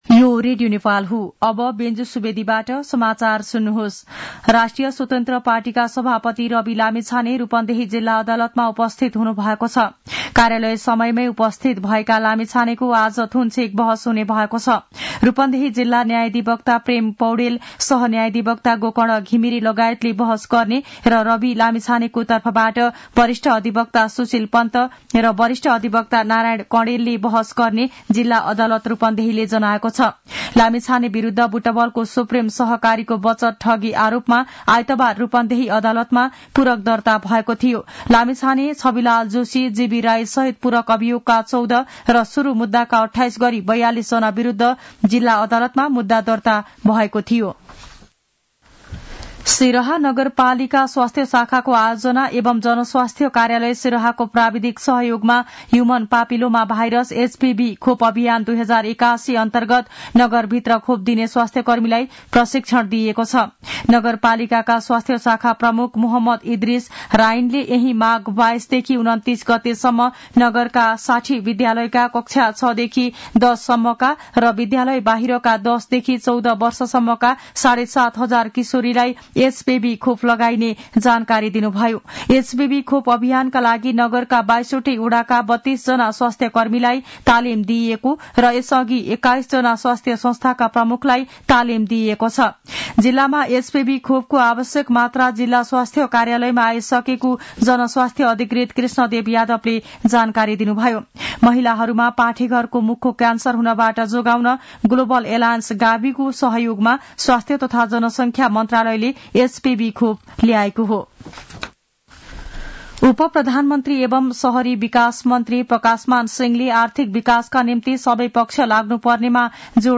मध्यान्ह १२ बजेको नेपाली समाचार : १० माघ , २०८१
12-am-news-2.mp3